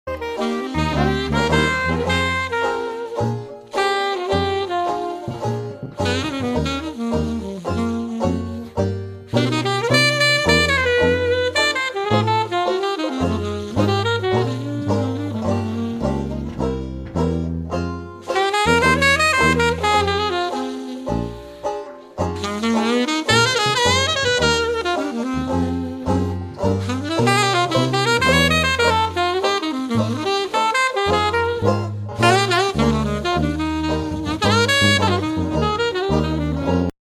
TRADITIONAL JAZZ
Traditioneller New Orleans Jazz . . .